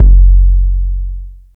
TR808BD.wav